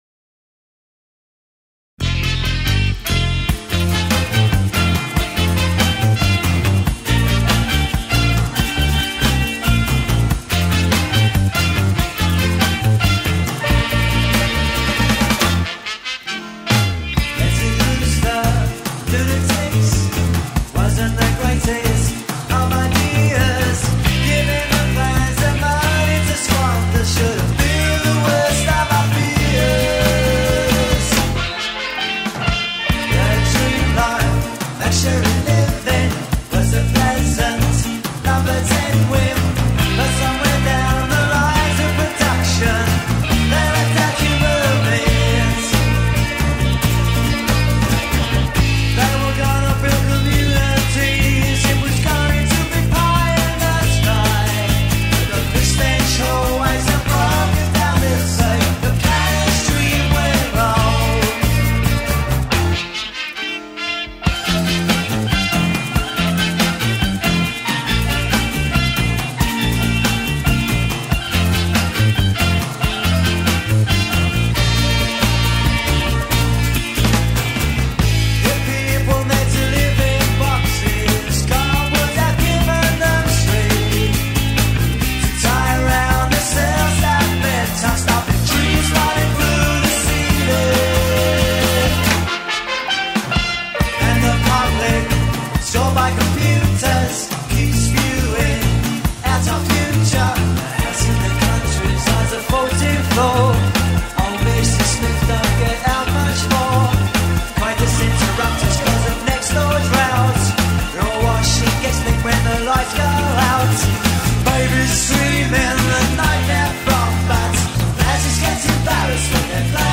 steel drums, aural sunshine and a strange